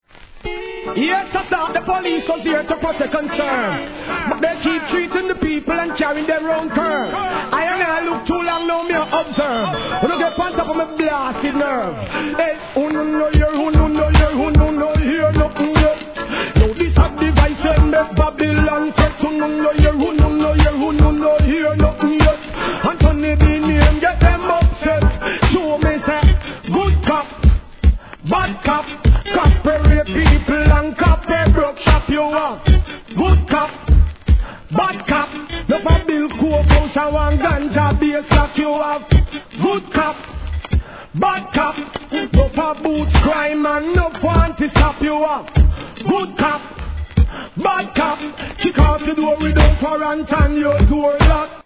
REGGAE
南国チックな気持ち良いイントロから最高